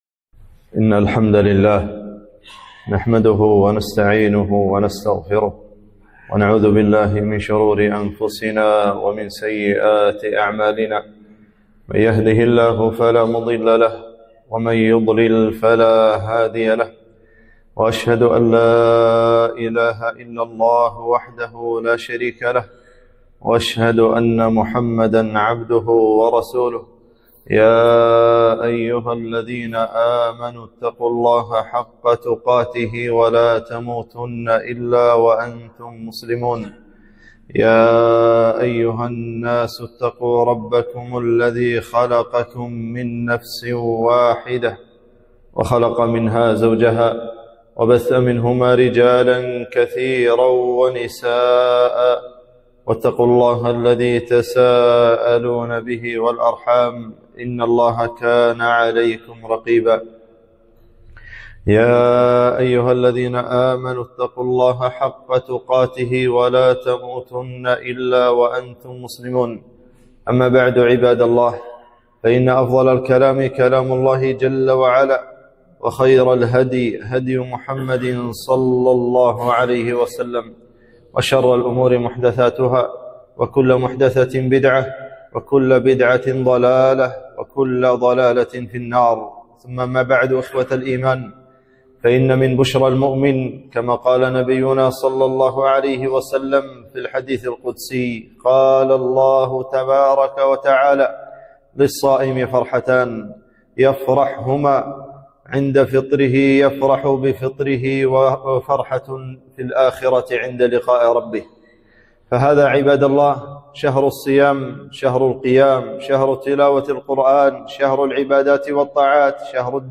خطبة - ماذا بعد رمضان؟